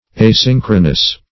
Asynchronous \A*syn"chro*nous\, a. [Gr. ? not + synchronous.]